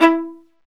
Index of /90_sSampleCDs/Roland - String Master Series/STR_Viola Solo/STR_Vla1 % marc